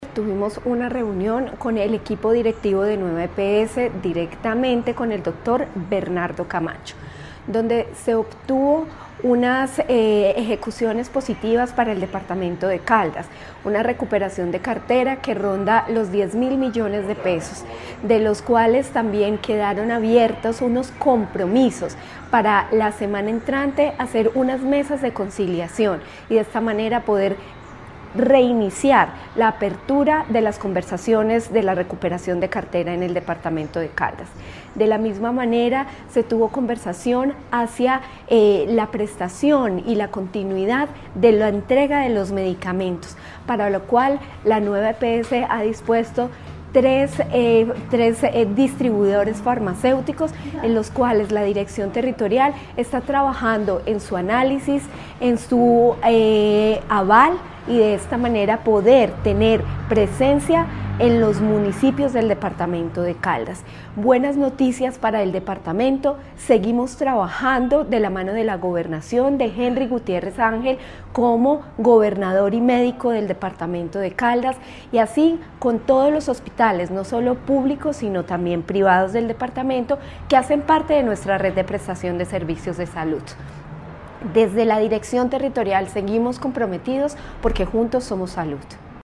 Natalia Castaño Díaz, directora de la DTSC.
AUDIO-NATALIA-CASTANO-DIAZ-DIRECTORA-DTSC-TEMA-VISITA-A-BOGOTA.mp3